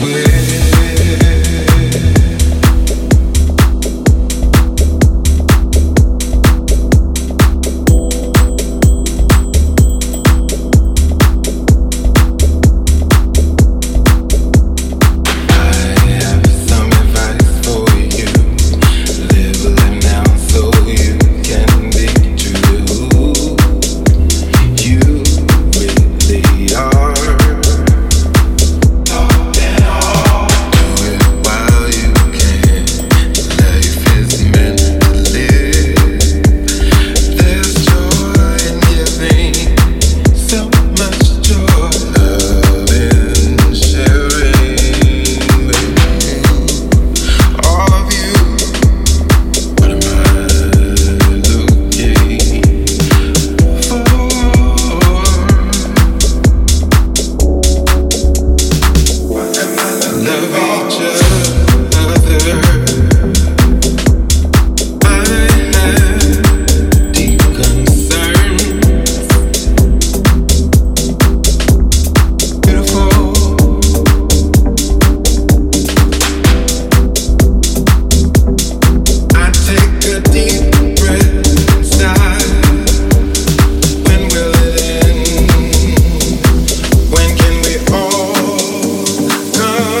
クラシカルでディープなソウルフル・ハウスをリリース！
ジャンル(スタイル) DEEP HOUSE / HOUSE